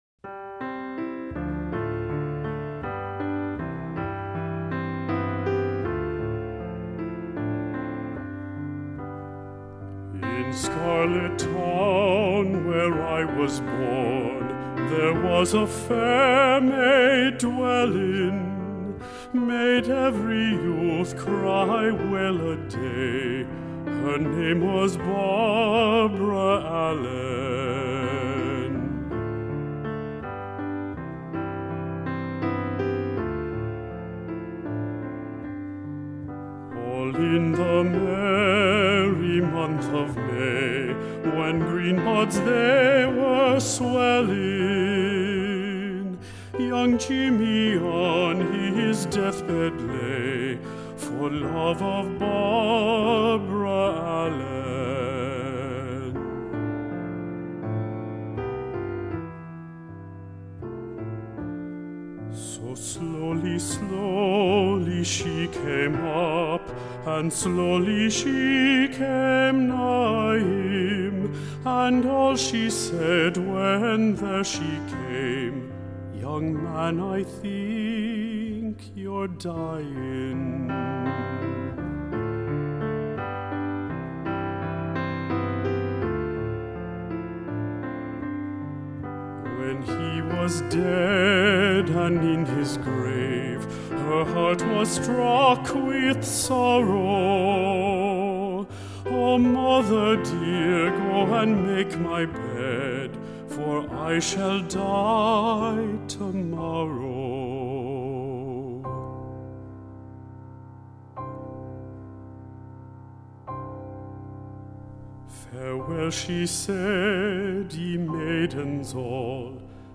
Medium-High Voice